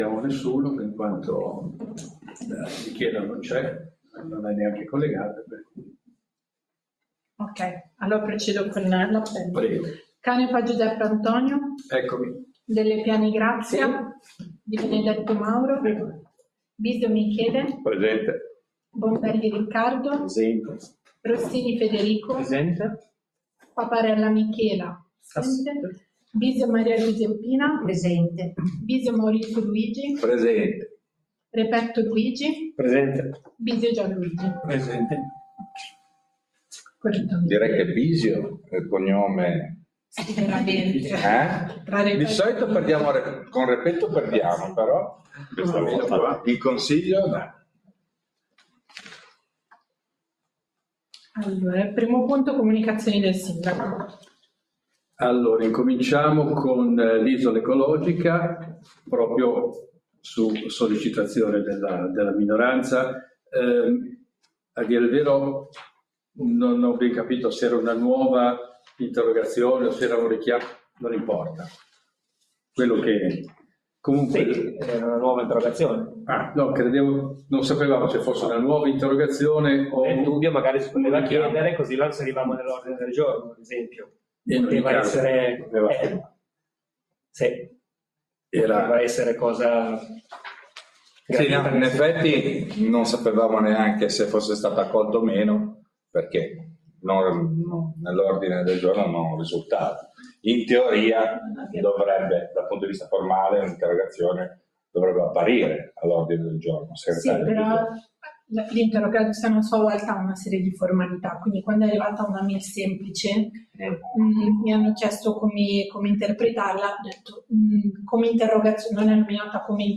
Seduta del Consiglio Comunale del 30/10/2025